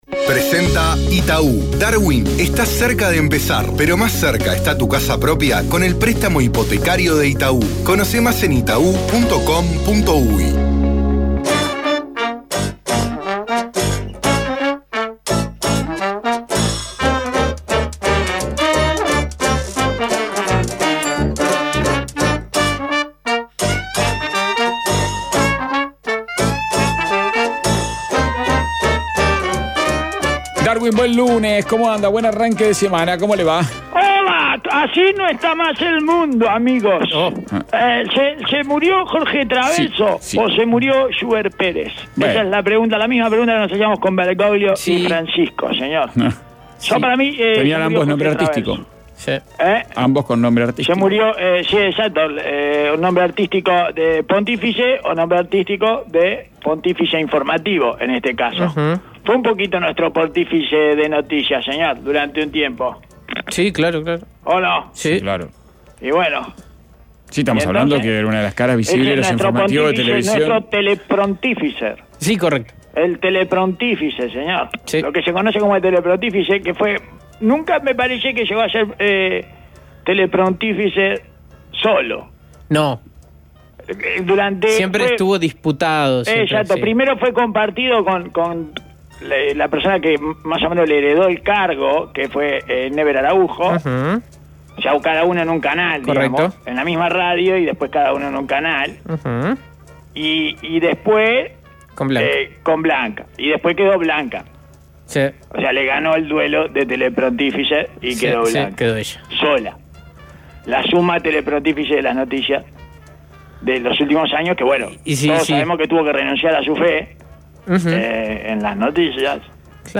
Darwin lee comunicado de Transhotel (pronúnciese Tranyotel), con redacción en base doble y autocrítica de los demás/ Historia de la ineficiencia útil